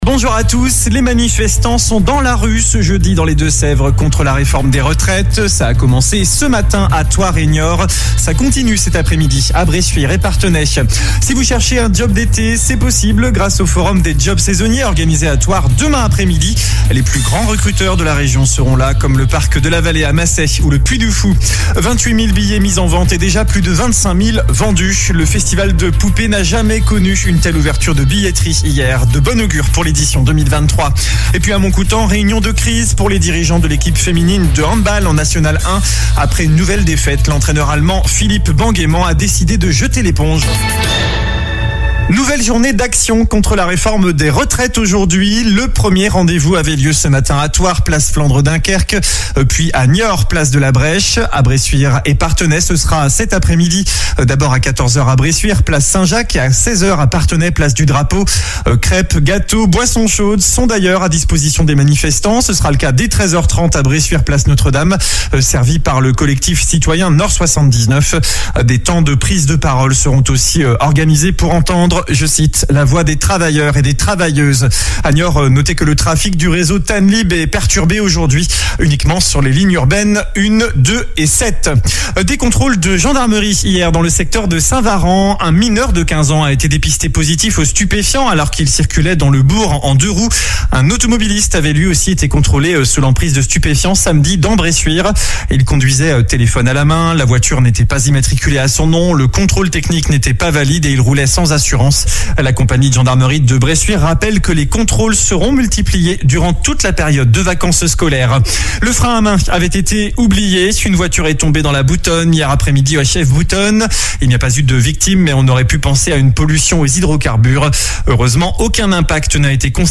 JOURNAL DU JEUDI 16 FEVRIER ( MIDI )